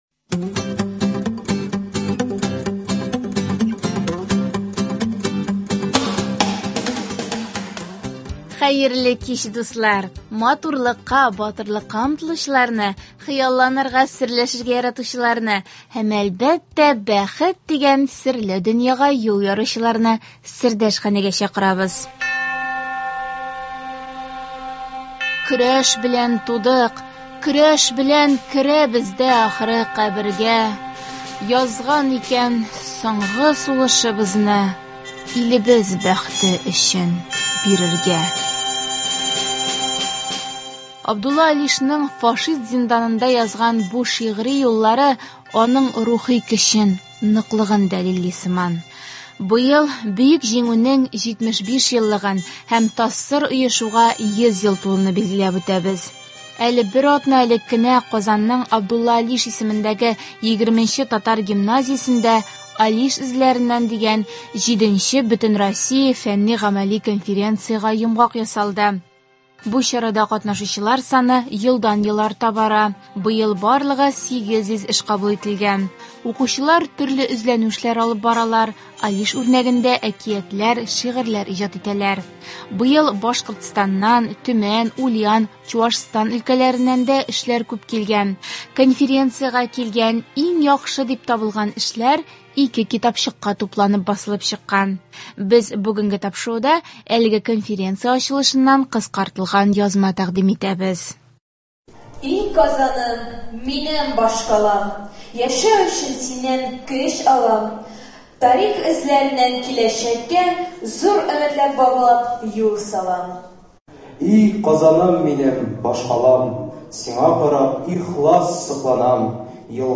Әле бер атна элек кенә Казанның Абдулла Алиш исемендәге 20нче татар гимназиясендә “Алиш эзләреннән” дигән җиденче Бөтенроссия фәнни- гамәли конференциягә йомгак ясалды. Без бүгенге тапшыруда әлеге конференция ачылышыннан кыскартылган язма тәкьдим итәбез.